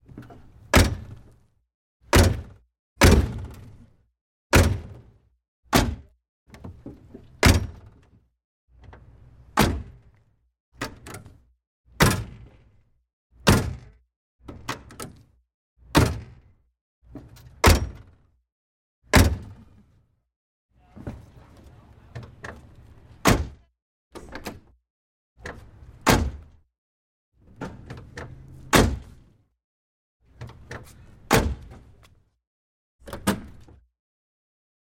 沃尔沃740 "钥匙丢失
描述：从沃尔沃740的点火器中拔出钥匙
标签： 拨浪鼓 沃尔沃 汽车 点火钥匙
声道立体声